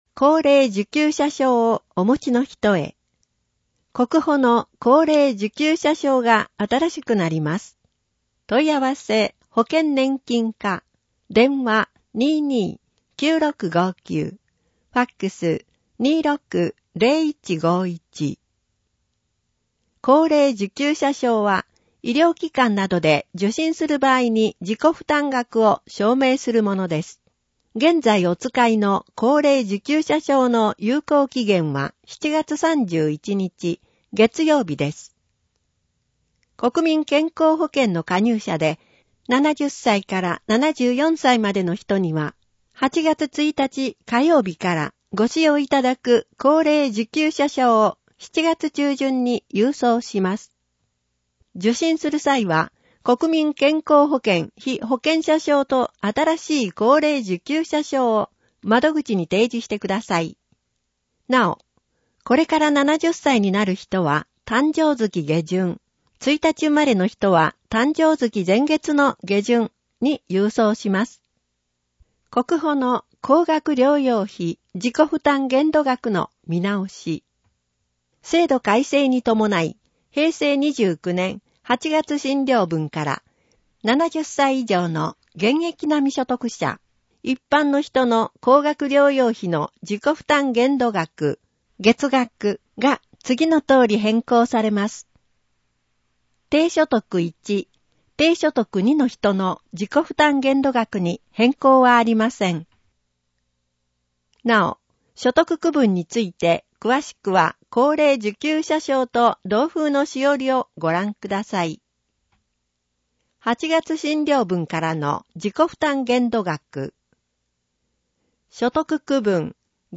※文字を読むことが困難な視覚障がい者や高齢者のために、ホームページ上で広報いが市「音声版」を公開しています。